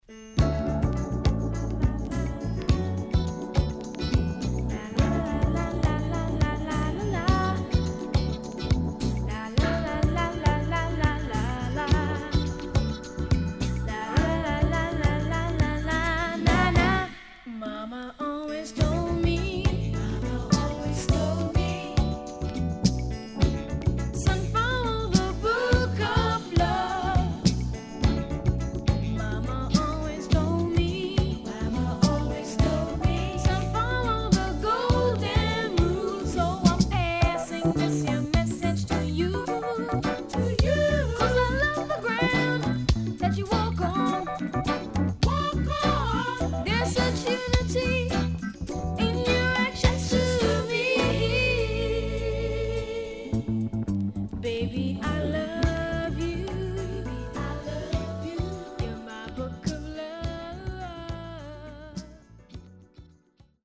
80年全曲Version接続のLong Mix!Superb Lovers Album
SIDE A:少しノイズ入りますが良好です。